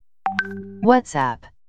Messages Ringtones